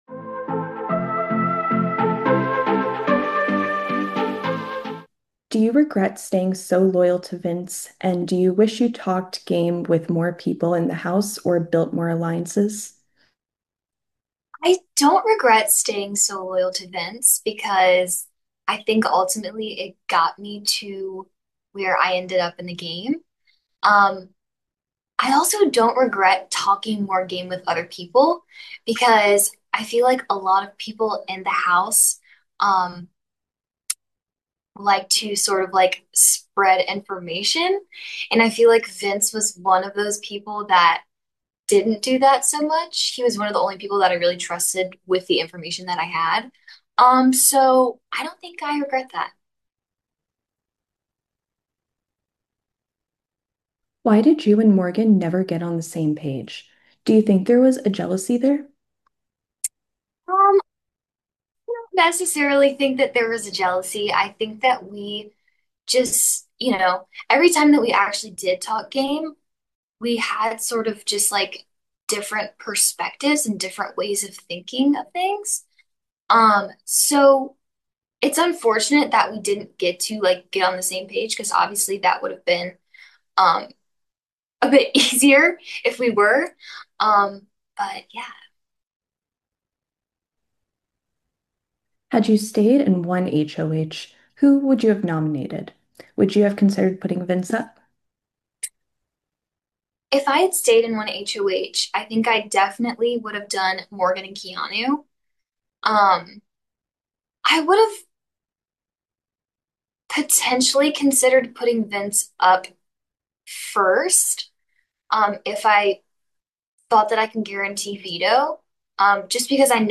Big Brother 27 Exit Interview